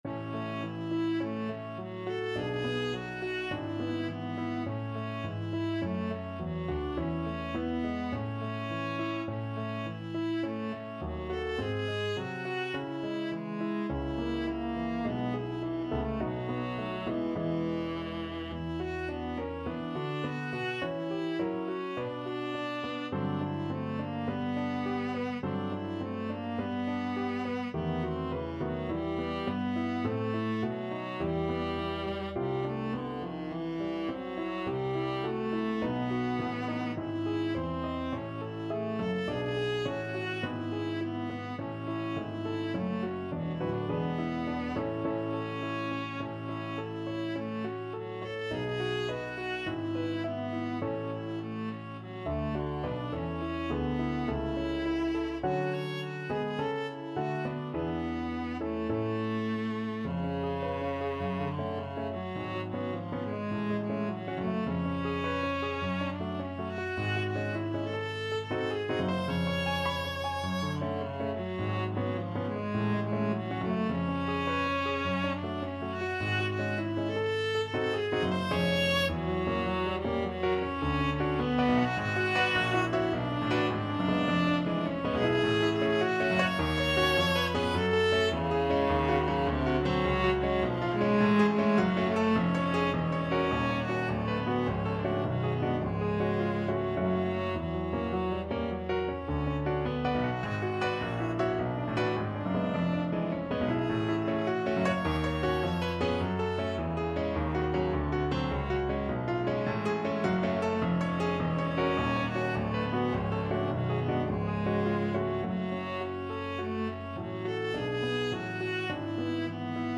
Viola version
4/4 (View more 4/4 Music)
Einfach, innig =104
Classical (View more Classical Viola Music)